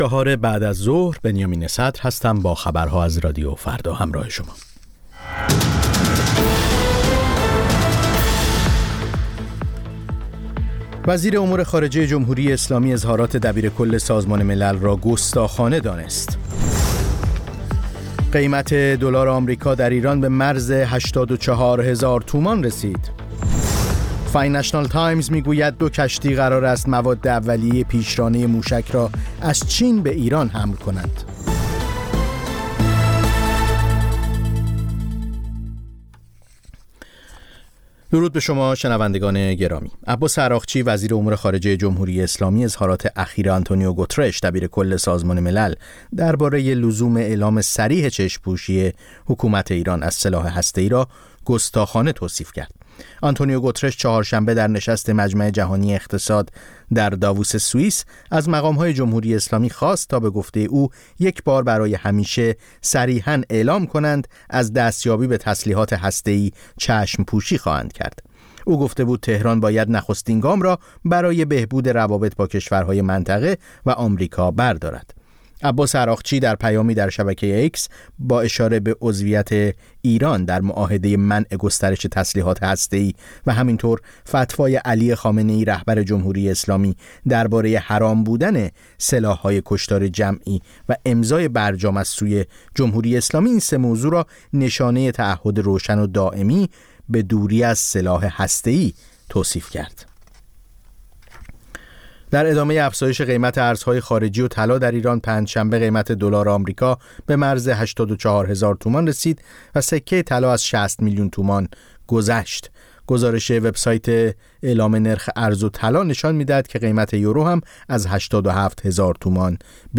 سرخط خبرها ۱۶:۰۰